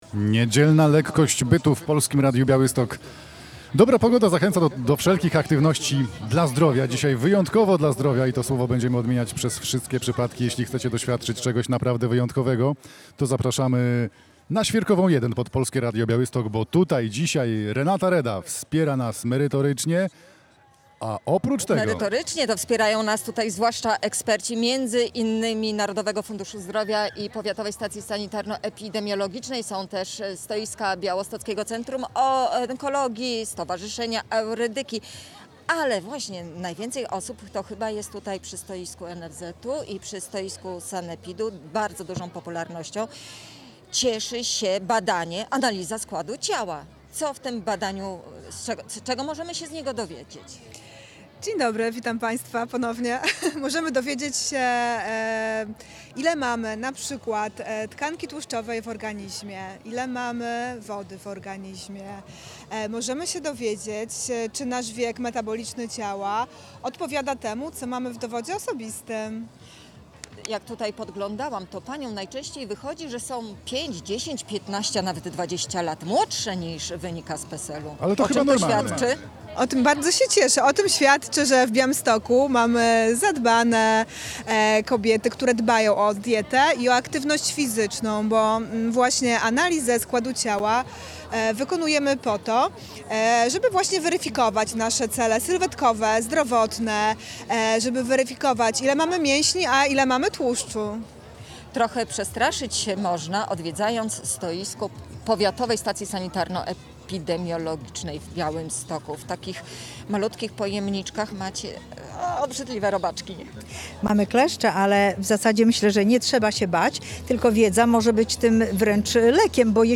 Analiza składu ciała na pikniku